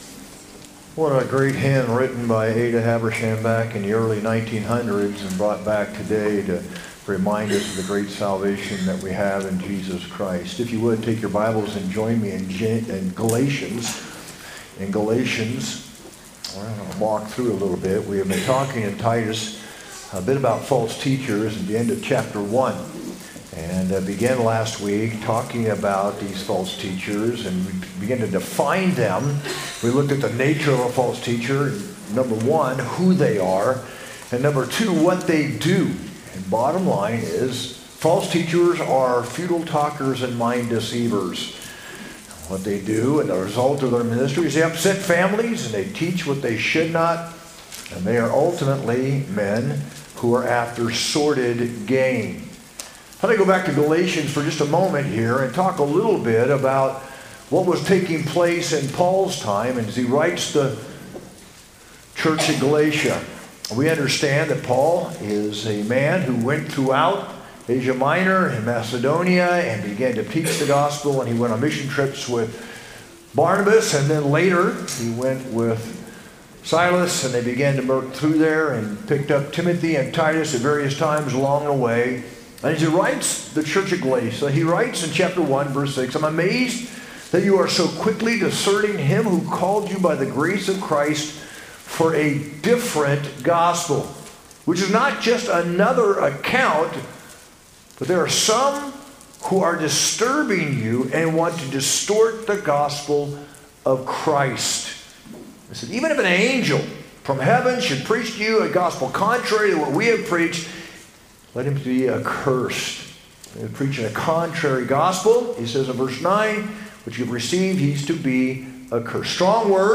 sermon-5-18-25.mp3